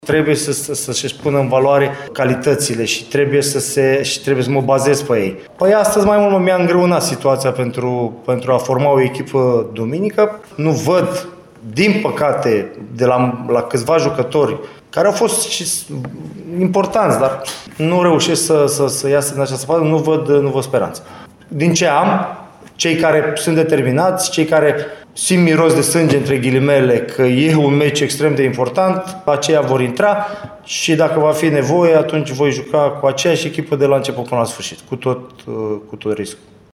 Legat de perspectivă, Mihalcea părea deja cu gândul la jocul important de campionat cu Metaloglobus, în vreme ce Neagoe făcea calcule pentru ieșirea din grupele de Cupa României: